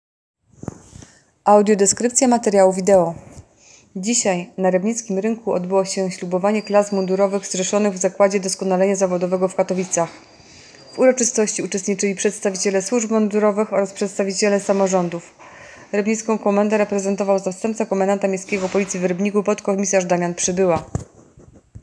Opis nagrania: Audiodeskrypcja.